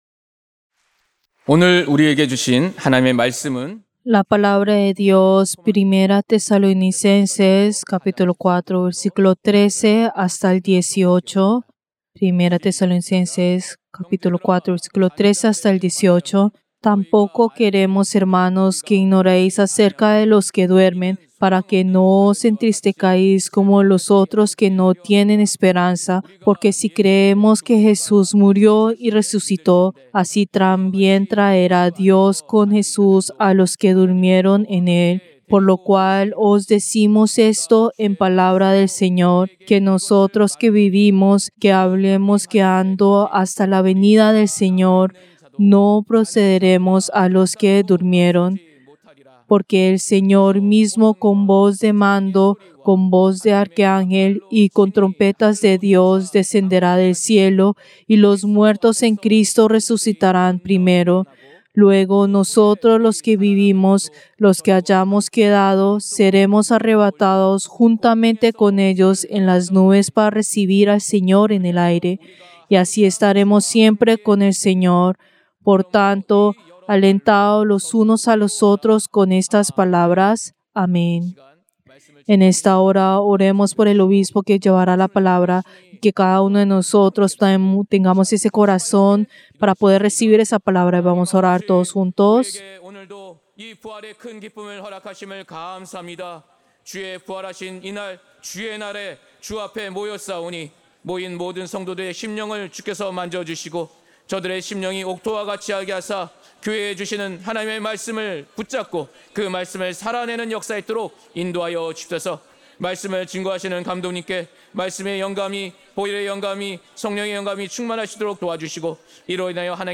Servicio del Día del Señor del 20 de abril del 2025